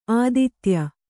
♪ āditya